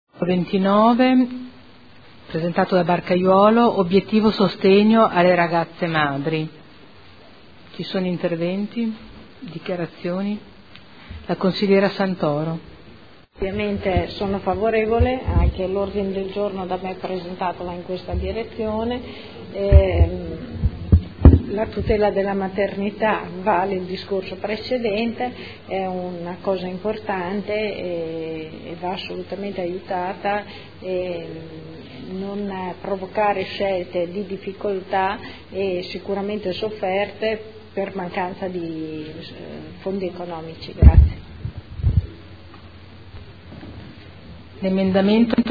Seduta del 13 marzo. Dichiarazioni di voto su singoli emendamenti o complessive